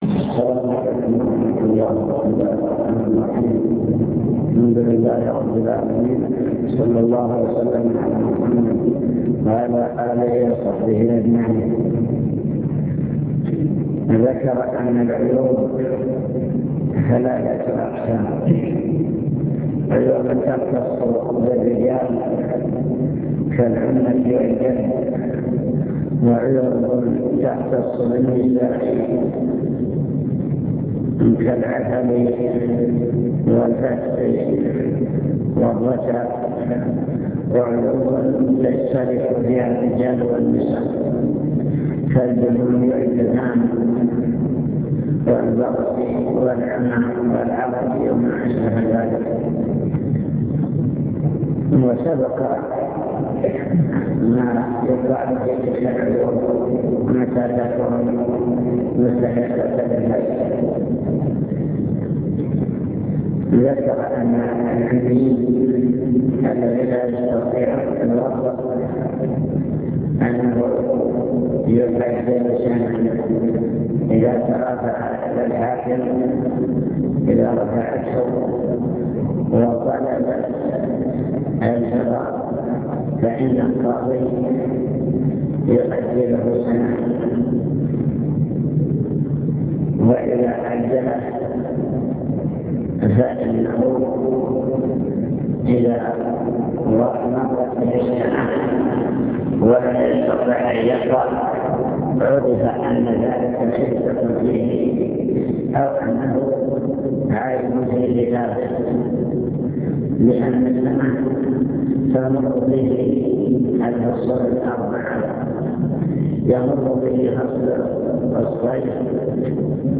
المكتبة الصوتية  تسجيلات - محاضرات ودروس  محاضرات في الزواج